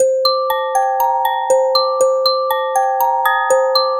Toy Piano.wav